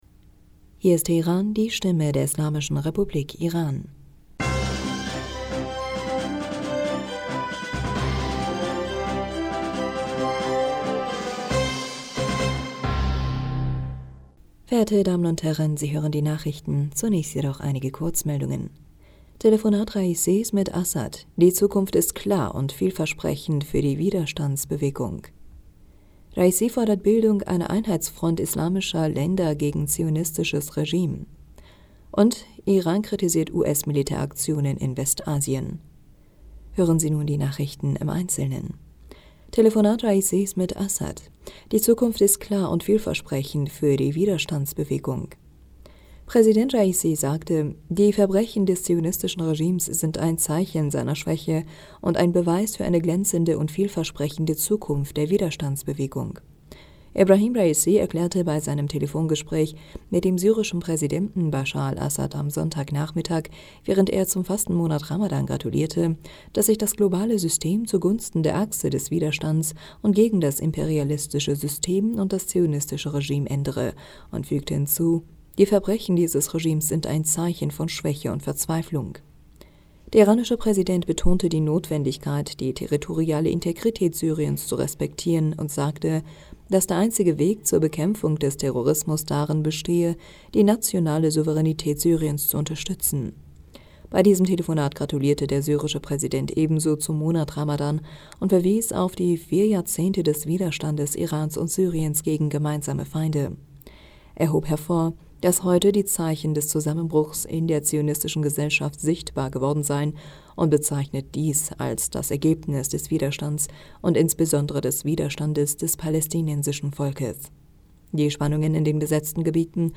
Nachrichten vom 10. April 2023